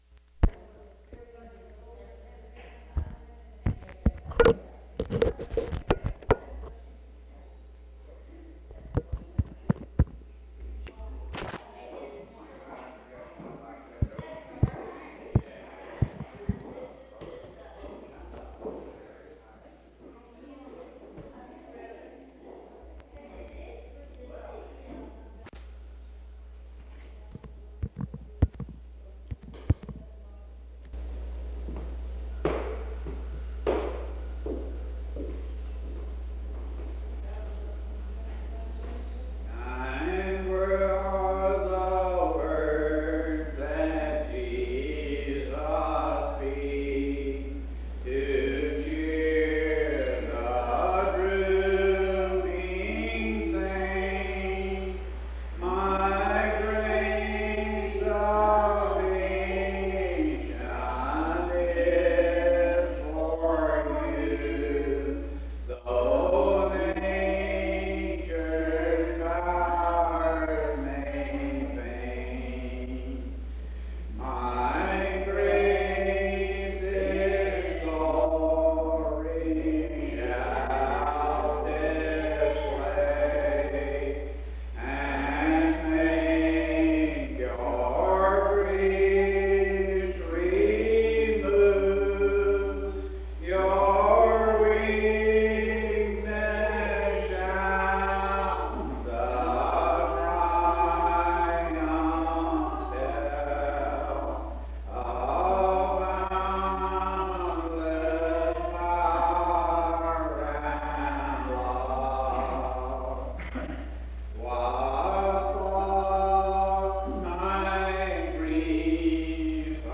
Herndon (Va.)